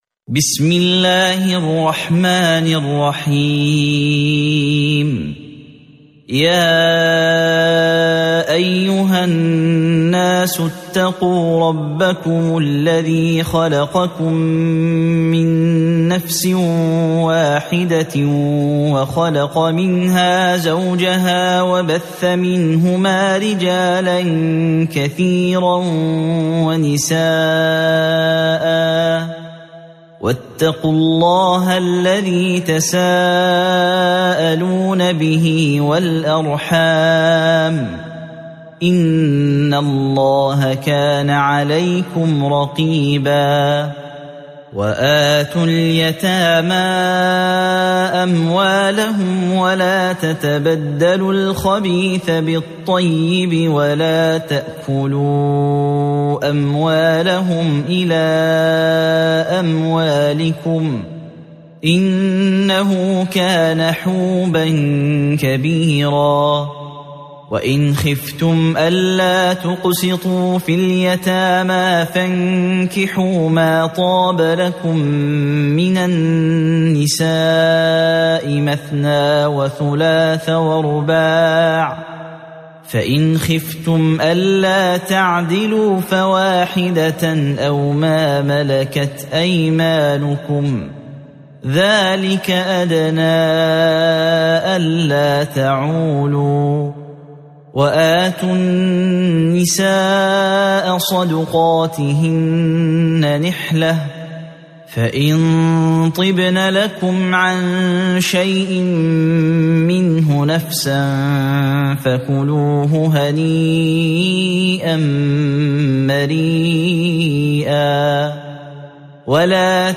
سورة النساء مدنية عدد الآيات:176 مكتوبة بخط عثماني كبير واضح من المصحف الشريف مع التفسير والتلاوة بصوت مشاهير القراء من موقع القرآن الكريم إسلام أون لاين